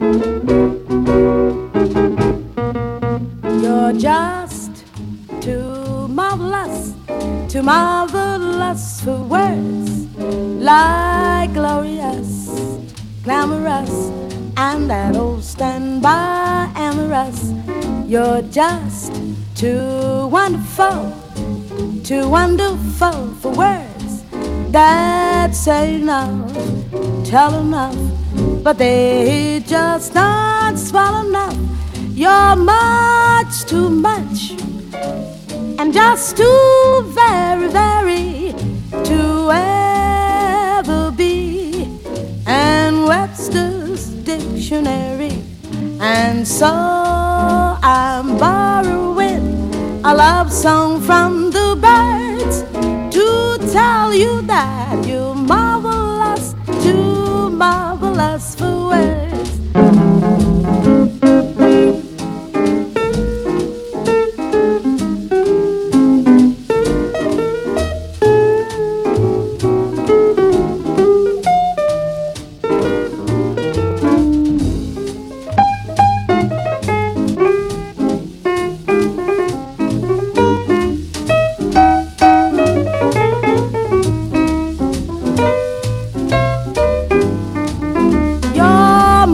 SOUL / RARE GROOVE/FUNK / LIBRARY / O.S.T. / JAZZ FUNK
イタリア産ライブラリー〜サントラ・グルーヴを贅沢に詰め込んだコンピレーション！